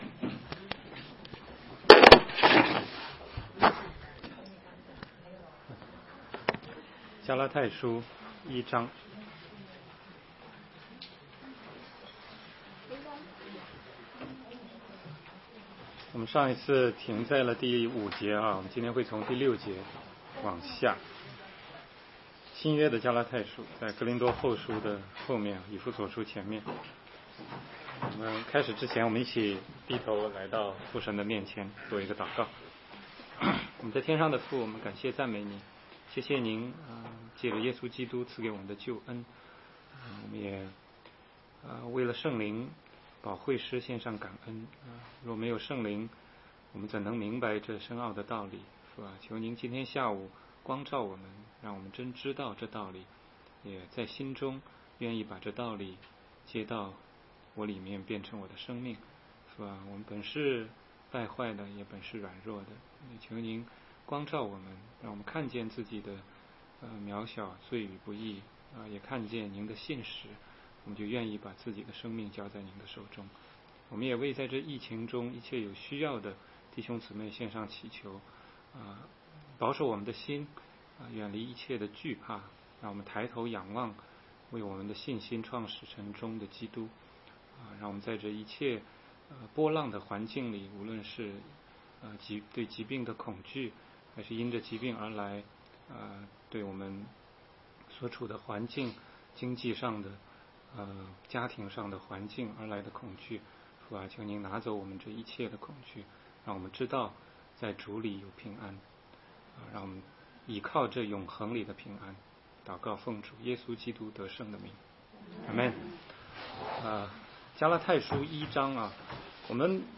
16街讲道录音 - 全中文查经